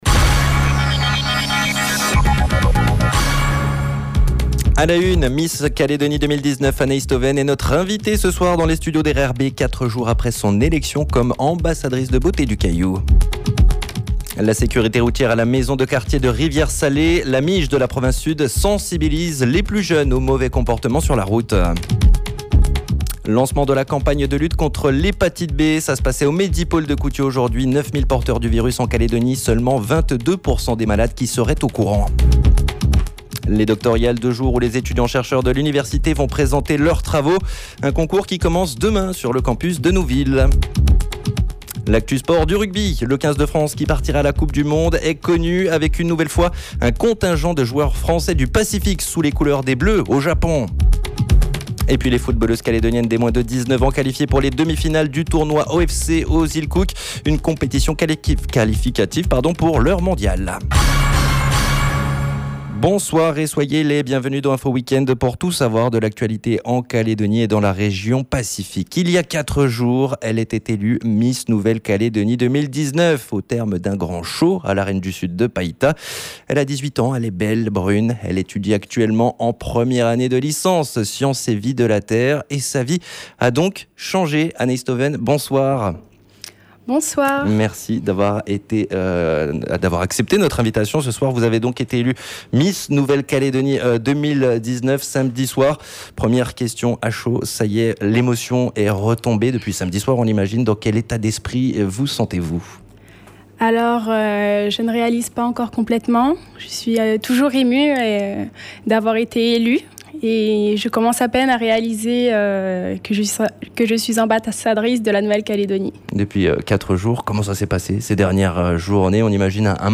JOURNAL : MARDI 03/09/19 (SOIR)